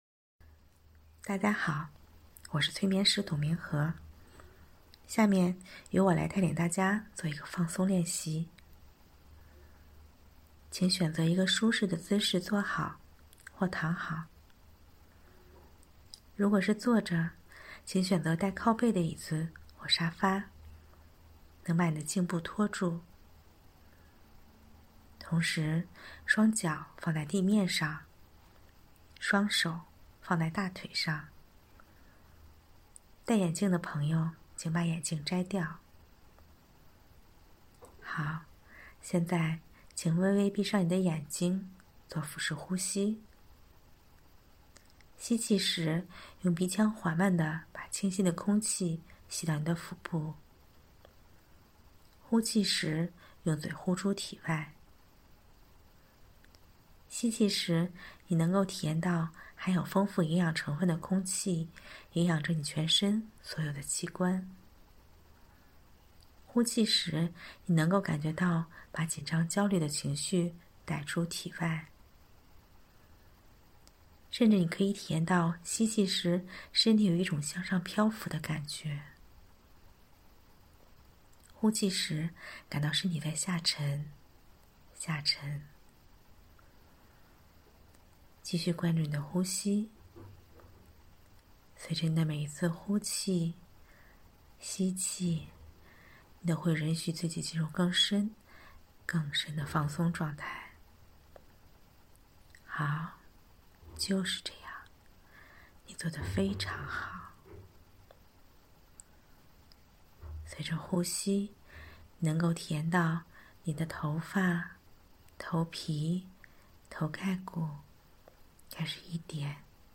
今天为大家推荐的小练习——身体扫描，是一个关注身体、和身体建立链接的练习。